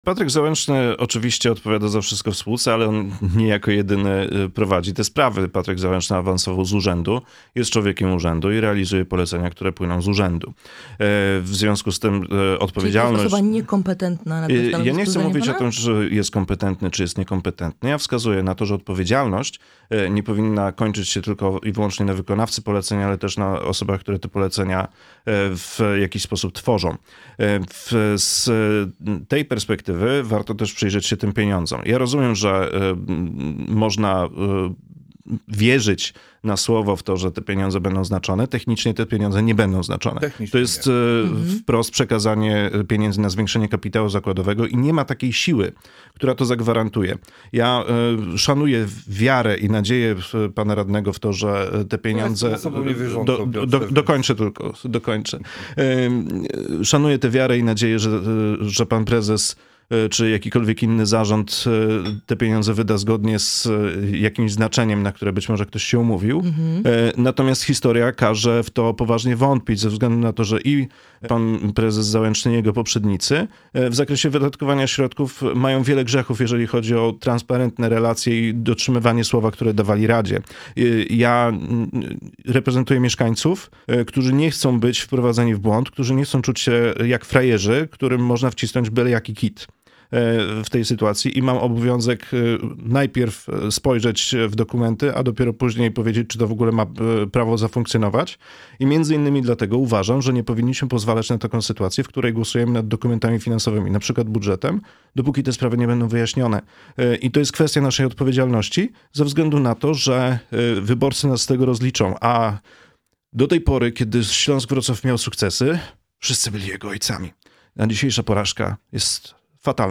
Radni miejscy Wrocławia Klubu Koalicji Obywatelskiej Robert Suligowski oraz Piotr Uhle w audycji „Poranny Gość” rozmawiali na temat budżetu miasta.
Mówi radny Piotr Uhle.